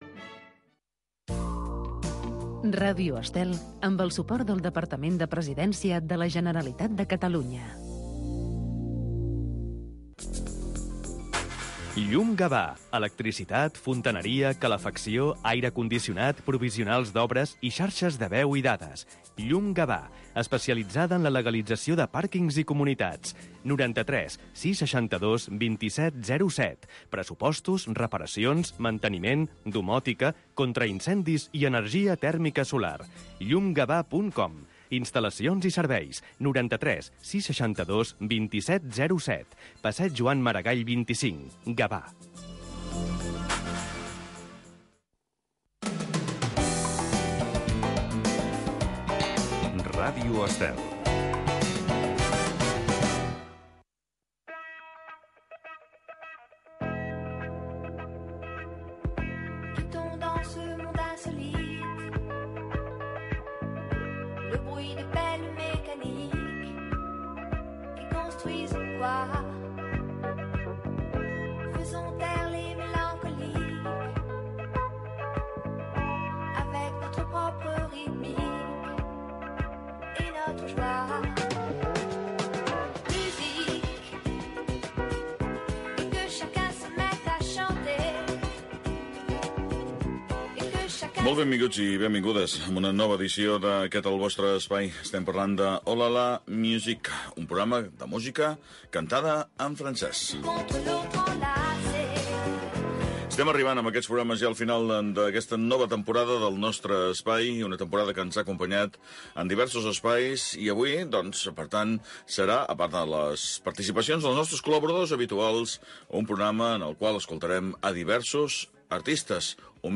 Oh la la, la musique. Programa de música francesa.